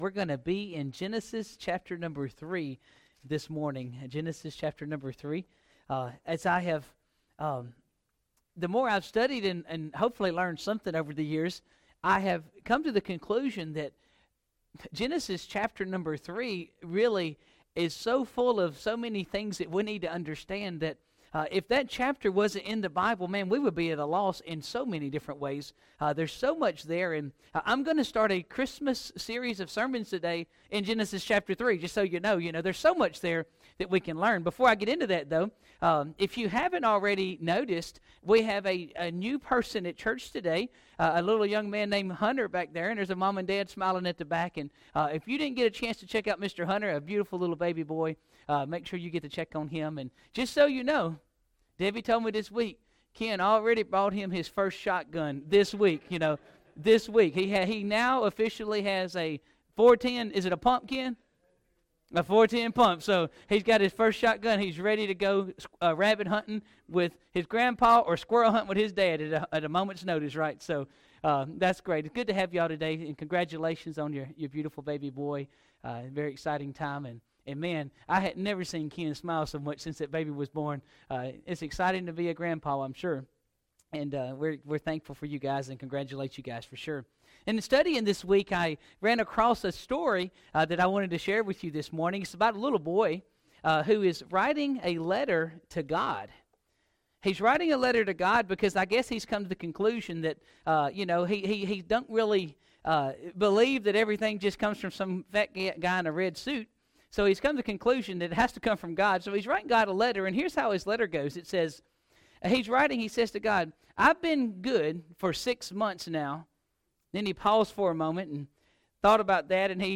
Sermons | Shady Grove Church